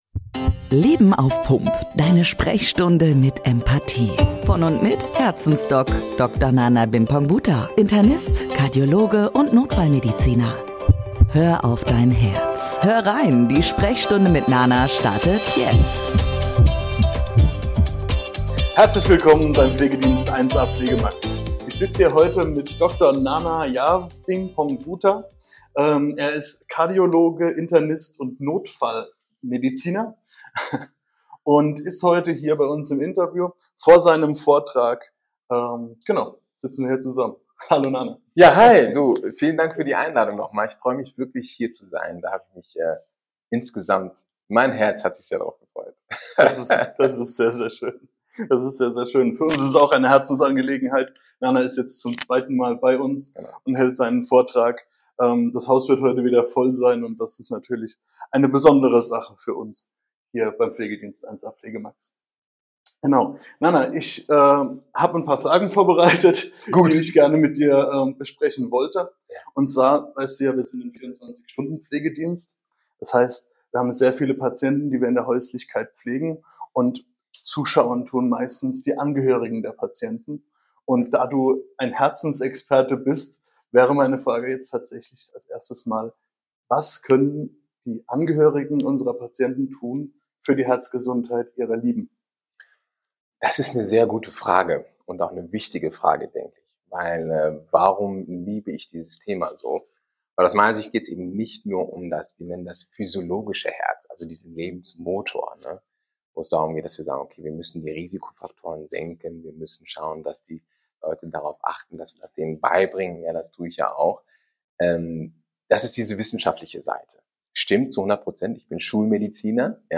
- Interview im Pflegedienst ~ LEBEN AUF PUMP Podcast